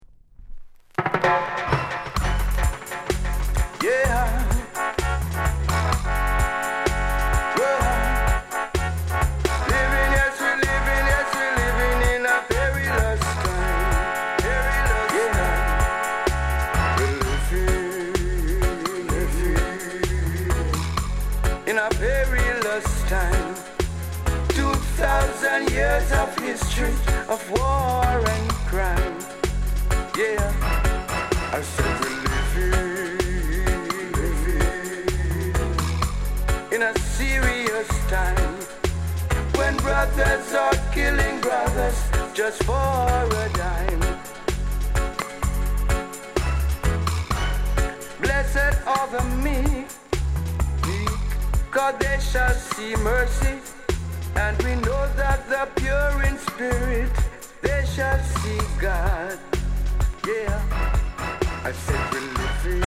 STEPPER ROOTS